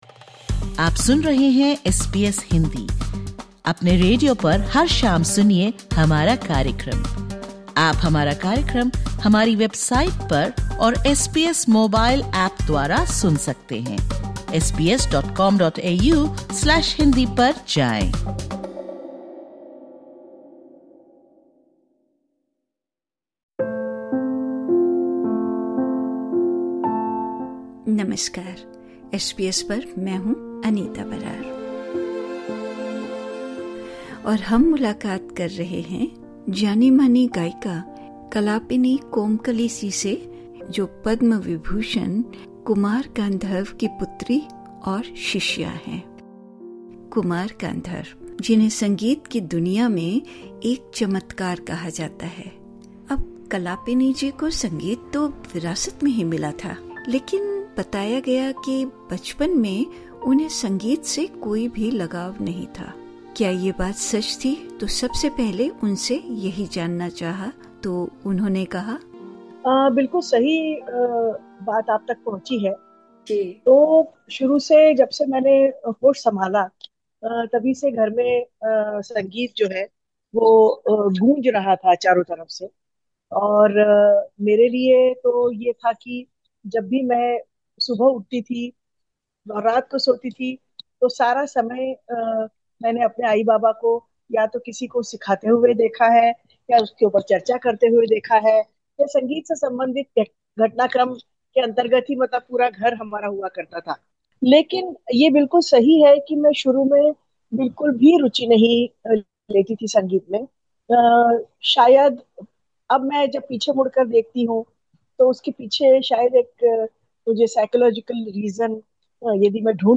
एस बी एस हिन्दी पर वह संगीत से अपने जुड़ाव, विरासत, और अपनी रचनात्मक शैली पर चर्चा कर रही हैं।